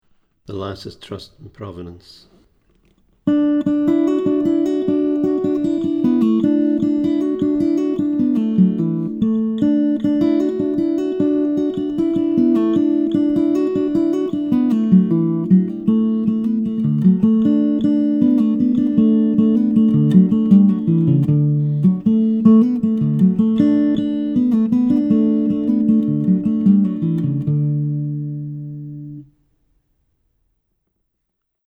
DIGITAL SHEET MUSIC - FINGERPICKING GUITAR SOLO
Celtic session tune, DADGAD tuning